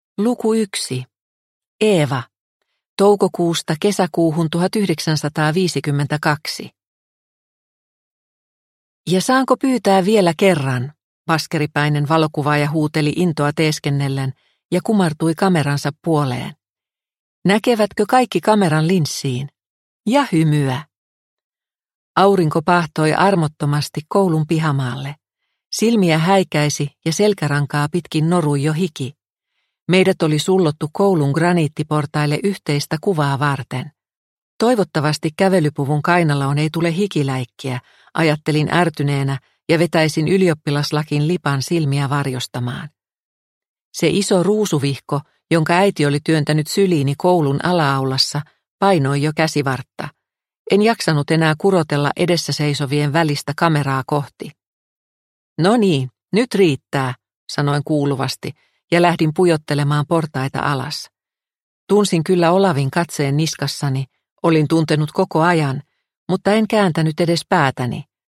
Metsäkukkia asvaltilla – Ljudbok – Laddas ner